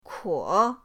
kuo3.mp3